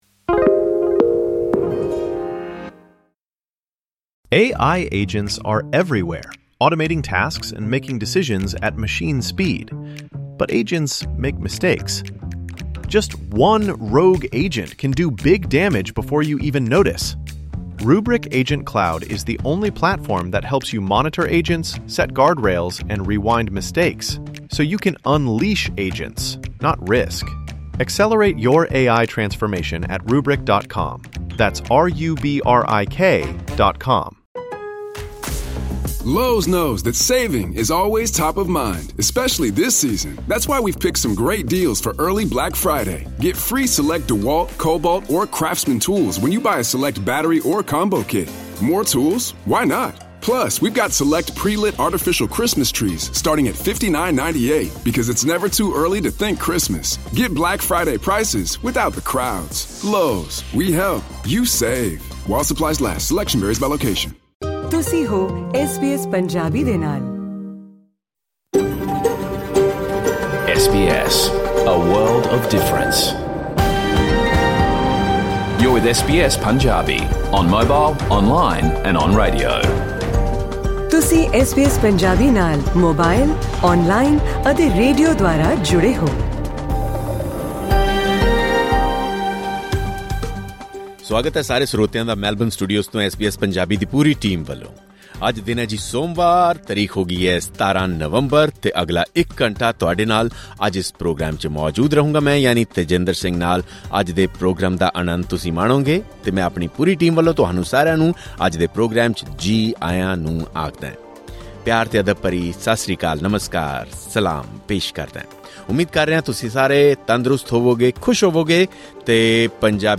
Listen to the complete Punjabi radio program of SBS Punjabi